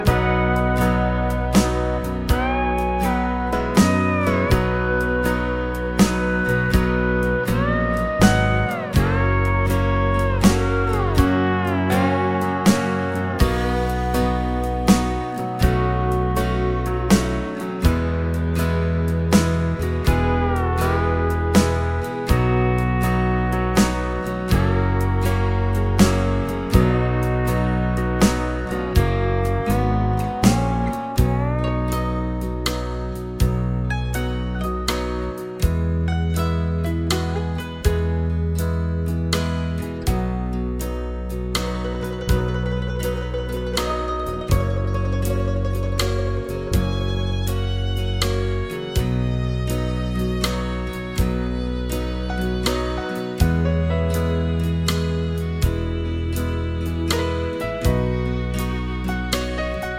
no Backing Vocals Duets 3:24 Buy £1.50